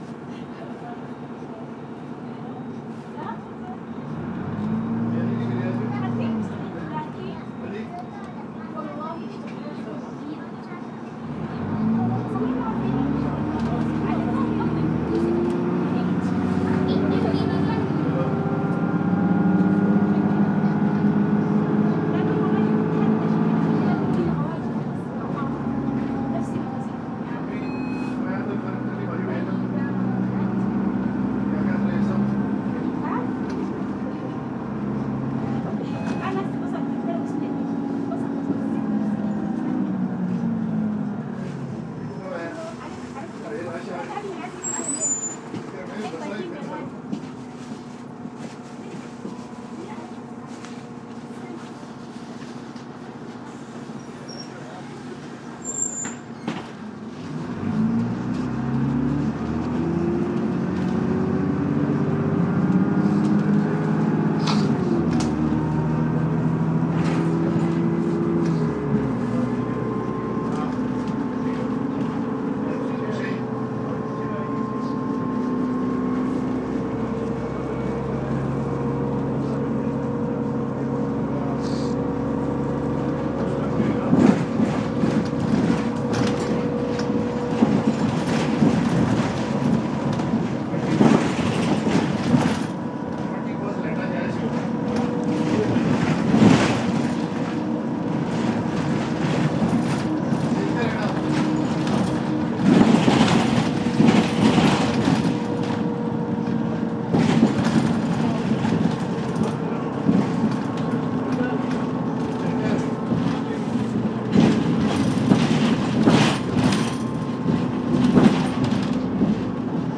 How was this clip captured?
The sounds from inside a West Midlands Travel No. 14 bus, travelling from Alum Rock Road to Birmingham city centre.